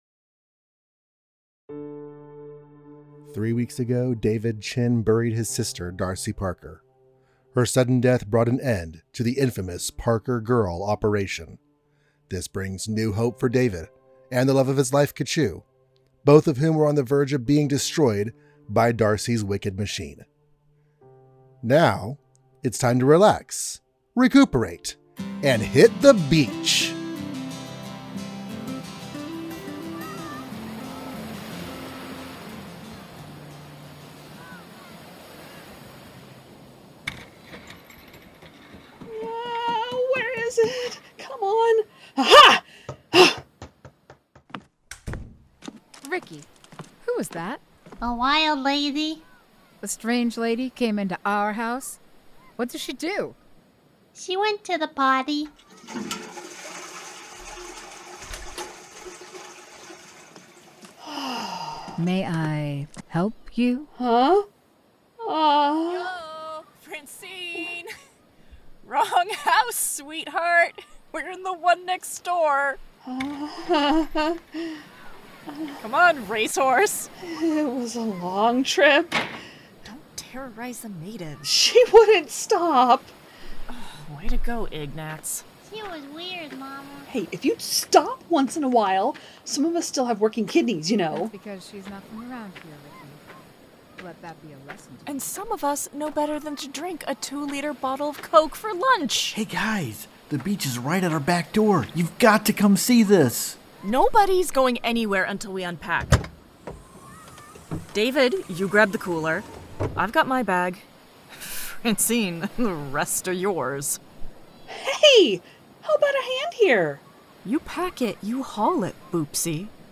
Strangers In Paradise – The Audio Drama – Book 8 – My Other Life – Episode 1 – Two True Freaks
The Ocadecagonagon Theater Group